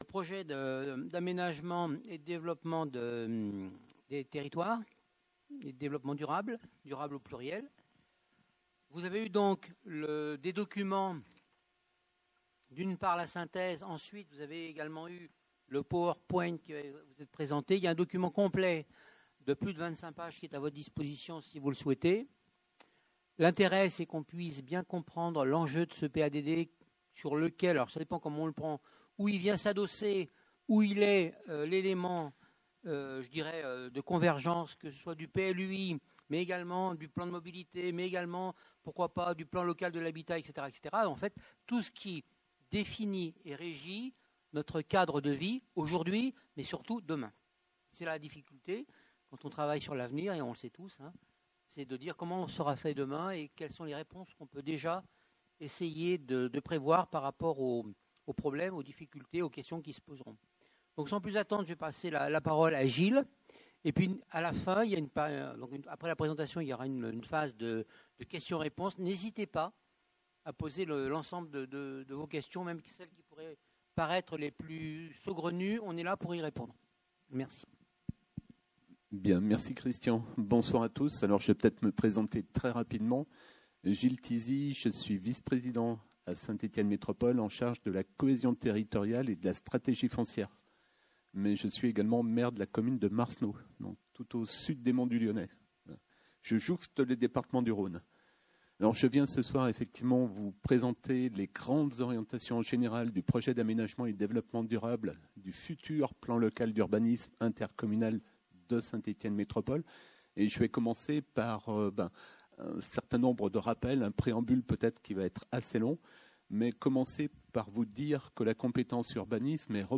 Conseil municipal du 4 novembre 2024 | Mairie de Saint-Priest-en-Jarez